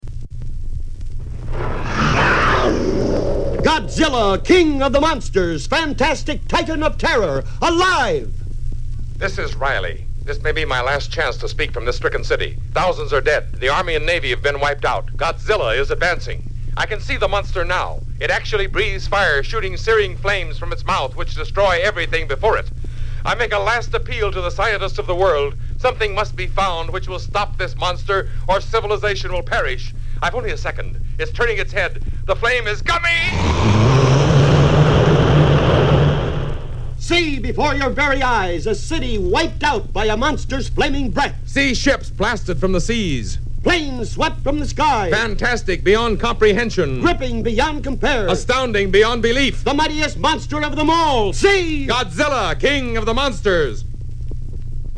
Oddly enough, Godzilla’s characteristic roar is not heard in these spots.
So, here they are…as listeners would have heard them back in 1956!
Godzilla King of the Monsters Radio Spots for 12, 50, and 100 seconds versions.